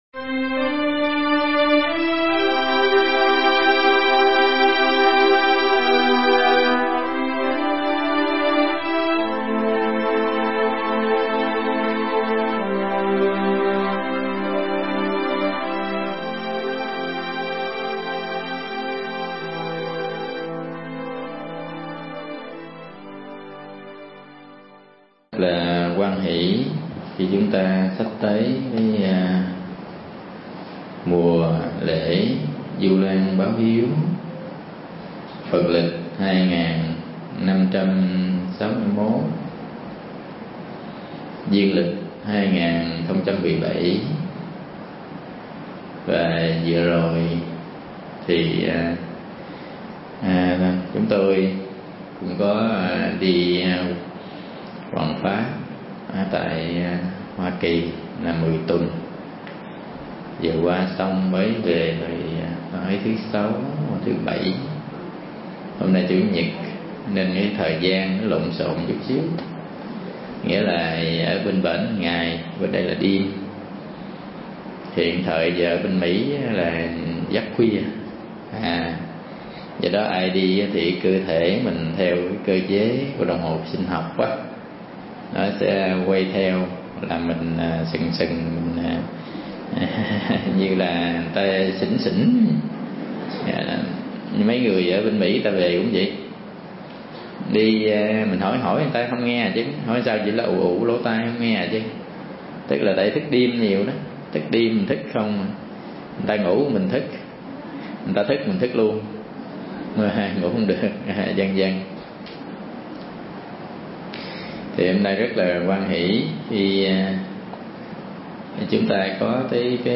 Mp3 Thuyết Giảng Bốn Kết Quả Tu
thuyết giảng tại Chùa Bửu Quang trong mùa Vu Lan báo hiếu